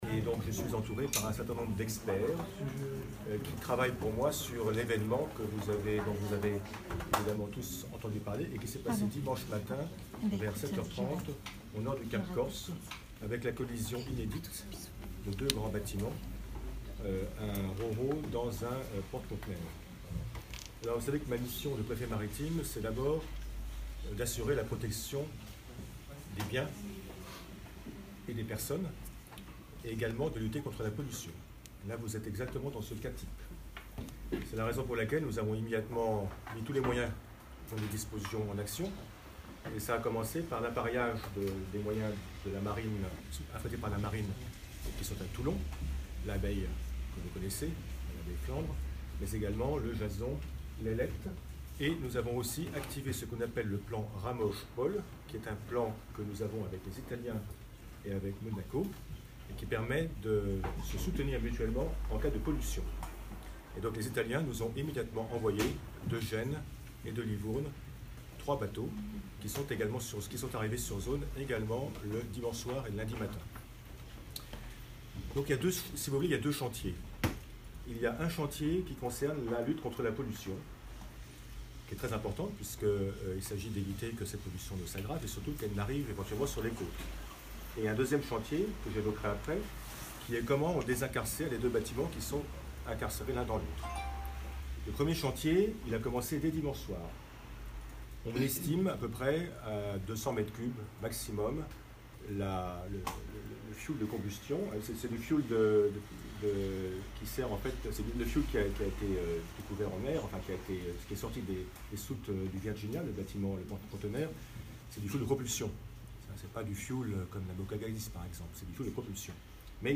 أكد مسؤول من السلطات البحرية في ندوة صحفية اليوم الأربعاء أن محاولة فصل السفينتين التونسية والقبرصية العالقتين قبالة سواحل كورسيكا الفرنسية بعد حادث اصطدام جدّ منذ الأحد الماضي، باءت بالفشل إلى حد الآن.